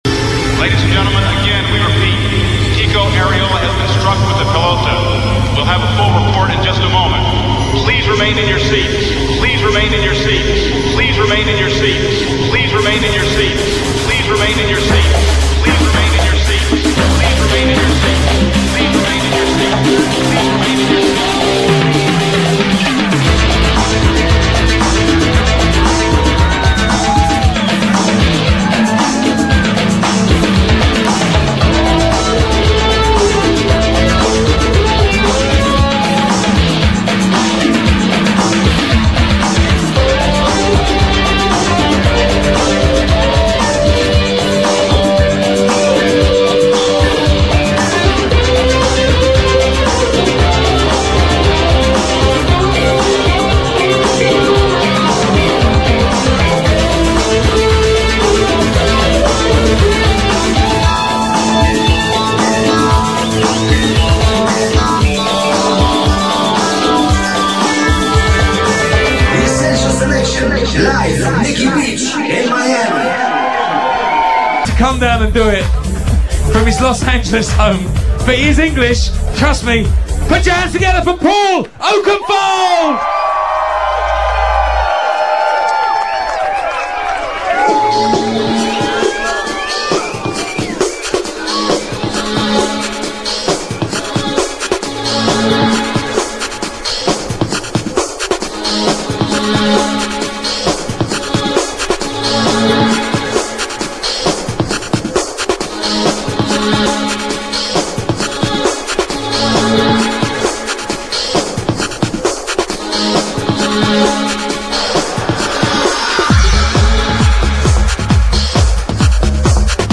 The liveset